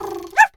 dog_2_small_bark_08.wav